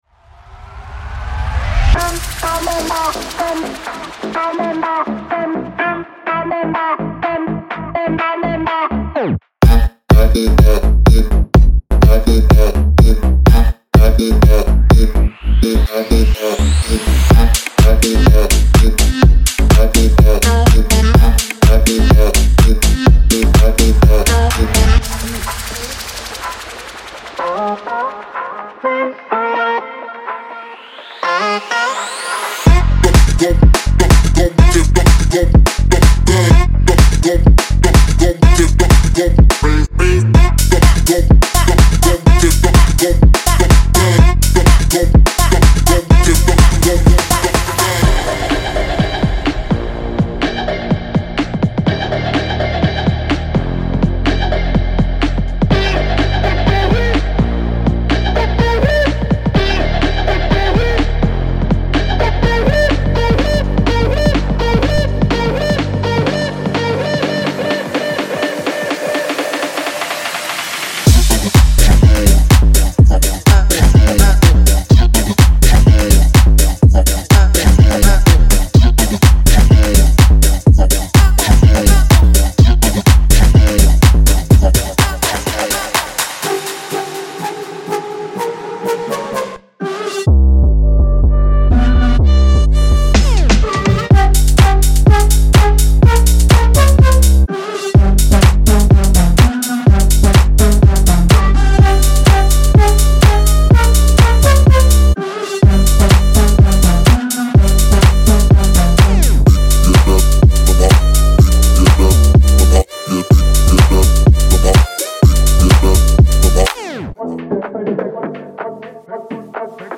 125 BPM
85个旋律声乐循环（干)
.115个声乐排音循环（干)
200个声乐镜头